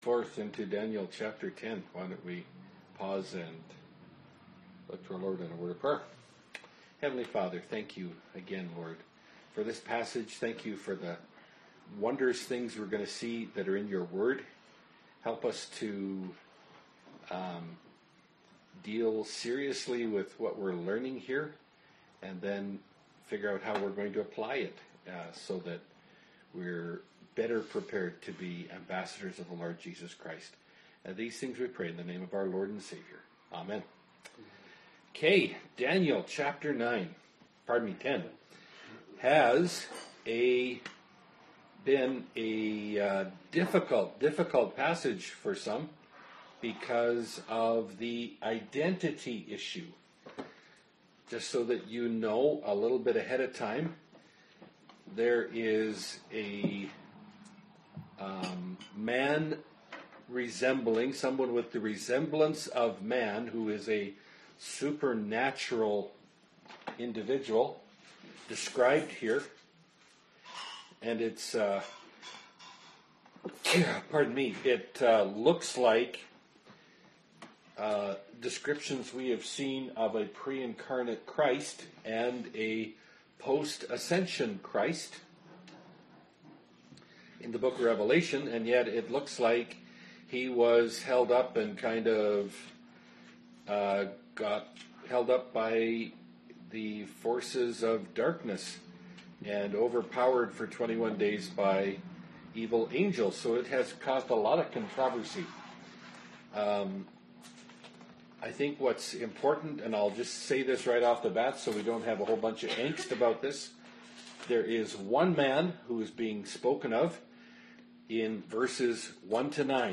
Bible Study – Daniel 10 – (2017)